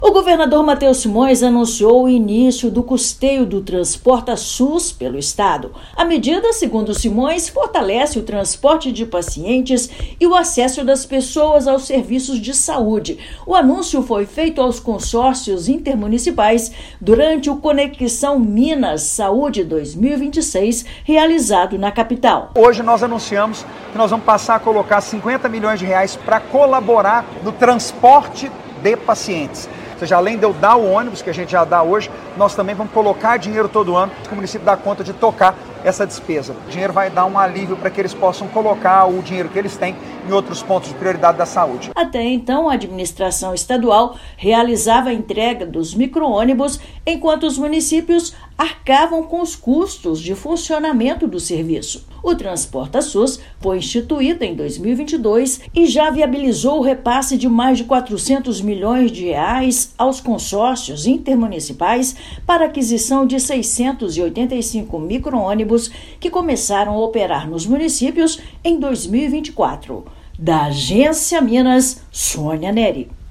Estado vai investir R$ 48 milhões no Transporta SUS-MG em 2026 e 2027. Ouça matéria de rádio.